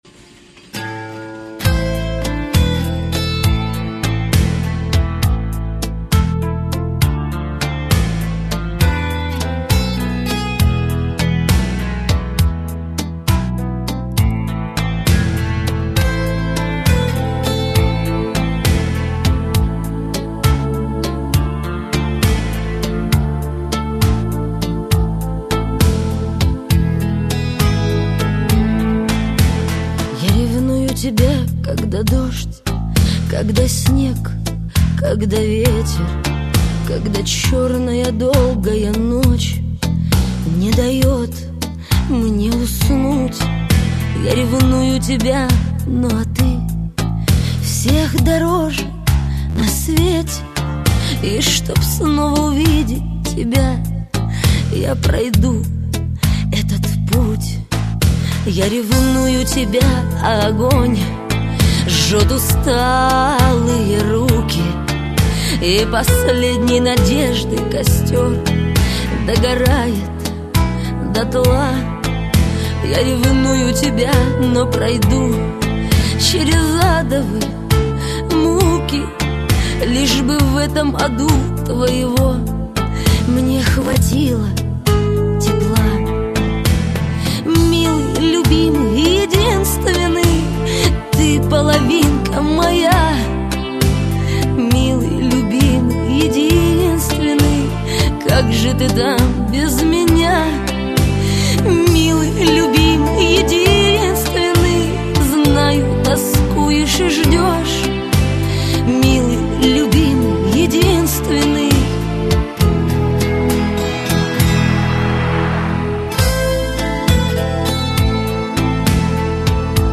Категория: Шансон